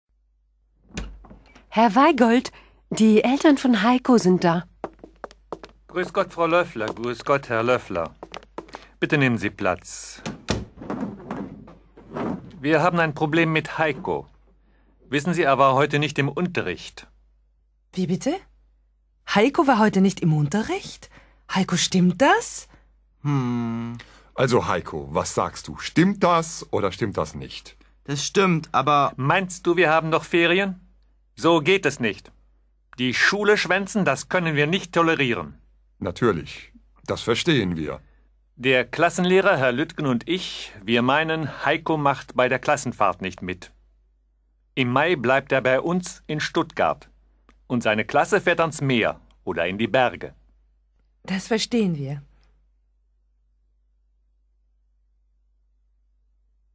Complète le dialogue entre le principal, Heiko et ses parents à l'aide du fichier son!